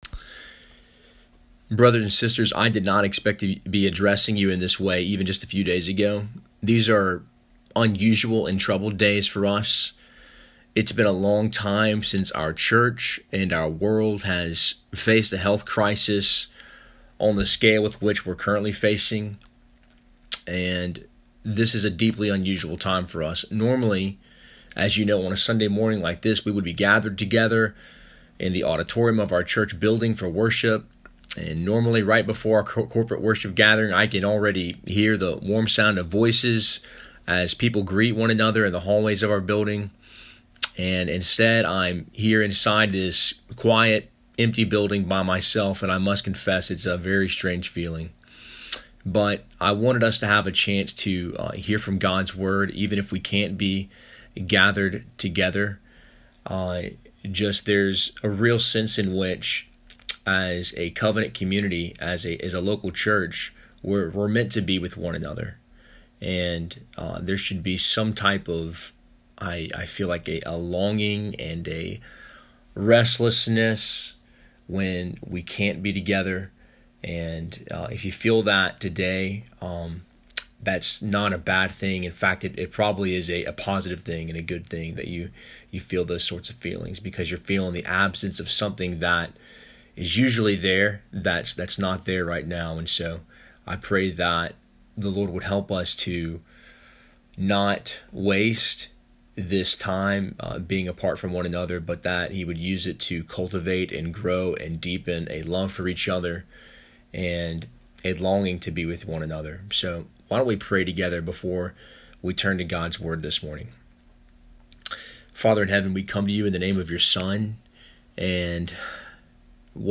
I’m sending you an MP3 file of a brief devotional meditation (20 min) I’ve prepared for you.